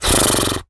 horse_breath_04.wav